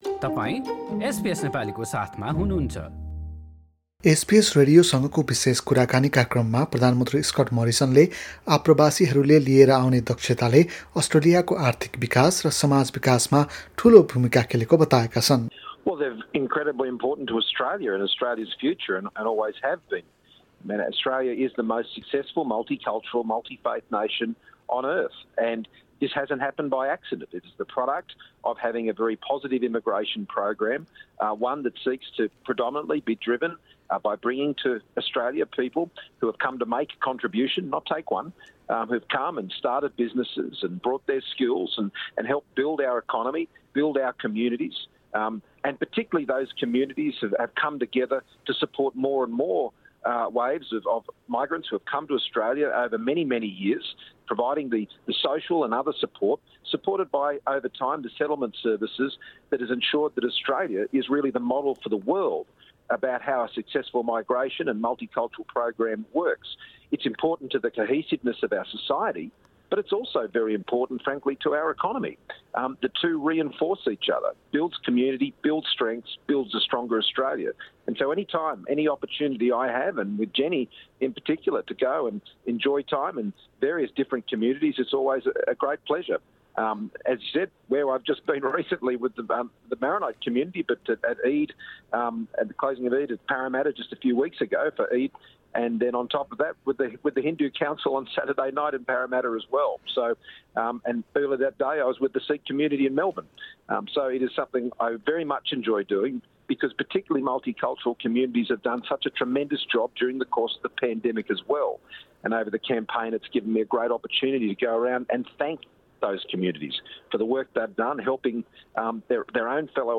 शनिवारका लागि तय भएको सङ्घीय निर्वाचन अघि प्रधानमन्त्री स्कट मोरिसनले एसबिएस रेडियोसँग कुरा गदै बहु सांस्कृतिक समुदायले अस्ट्रेलियाको आर्थिक भविष्य निर्माण गर्नमा ठुलो भूमिका खेलेको बताएका छन्। बहु सांस्कृतिक समुदायले कसरी मुलुकलाई उन्नत बनाउँछ भन्ने कुराको विश्वव्यापी उदाहरण अस्ट्रेलिया बनेको पनि उनले बताएका छन्।